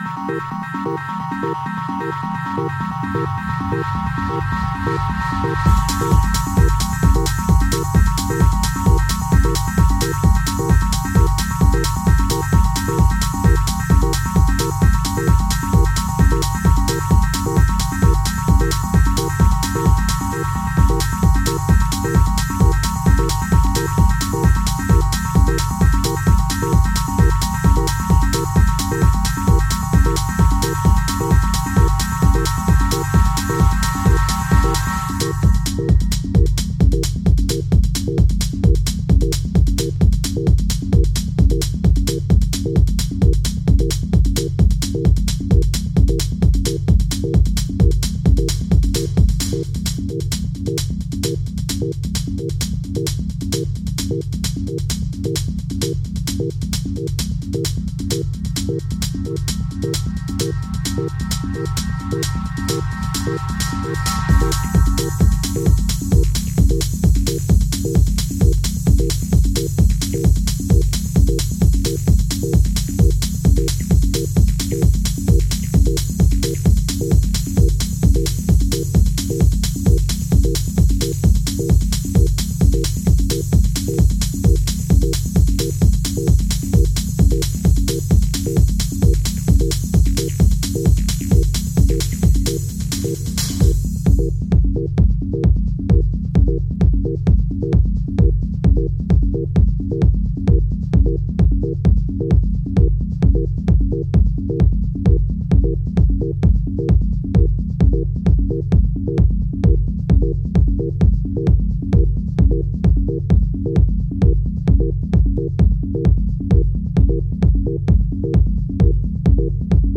supplier of essential dance music
Techno